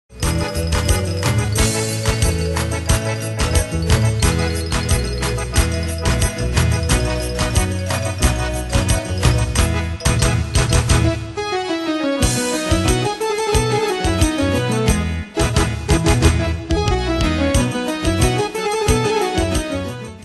Style: Portugal Ane/Year: 1984 Tempo: 90 Durée/Time: 2.34
Pro Backing Tracks